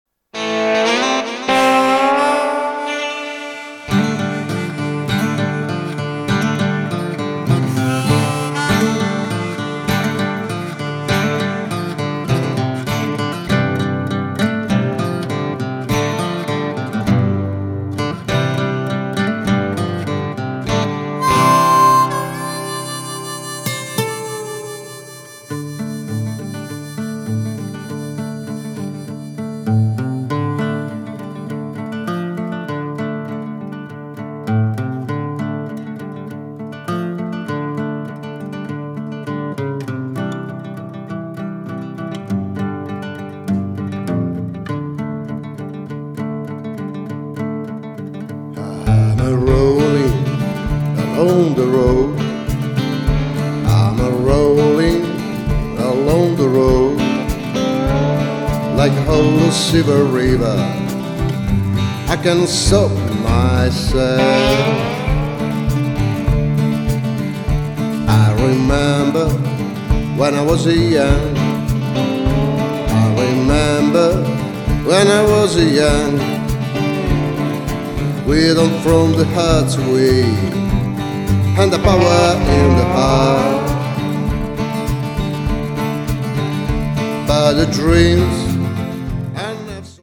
drum percussions (2, 3, 6)
guitar (6, 8)
ingioiellano l'architettura musicale di un blues viscerale